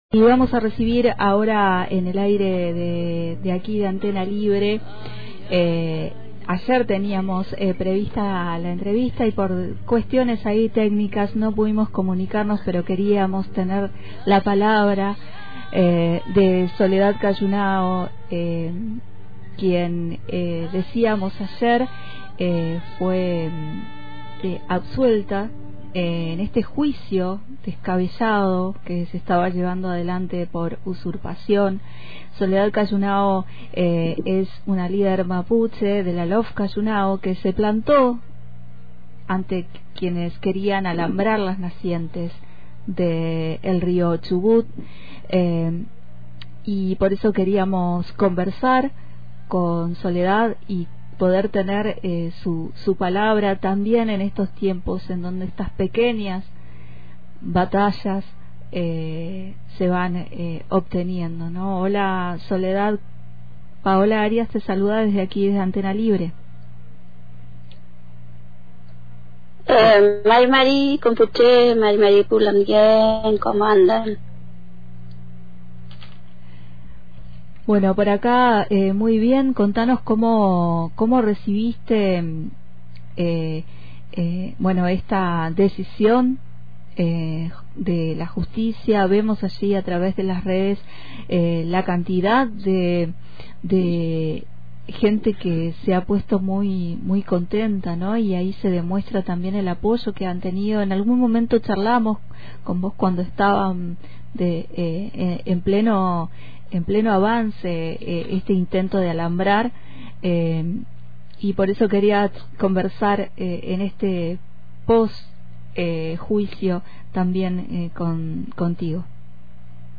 En diálogo con Antena Libre, sostuvo que la criminalización busca silenciar a quienes denuncian el avance sobre las nacientes del río Chubut y alertó que detrás del alambrado y la compra de tierras hay un interés creciente por las fuentes de agua. Remarcó que no se trata sólo de un problema de una comunidad, sino de la defensa de un bien vital para toda la Patagonia.